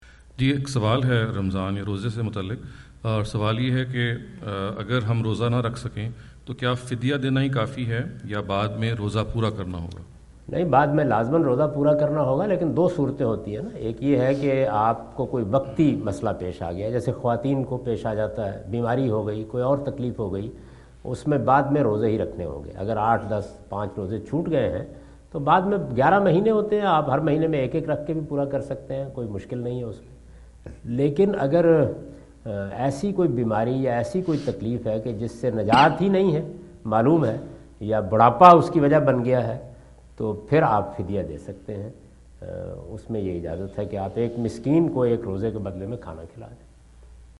Category: Foreign Tours / USA 2017 / Questions_Answers /
Javed Ahmad Ghamidi answer the question about "Is it Enough to Compensate Financially for Missed Fasts? " During his US visit in Dallas on October 08,2017.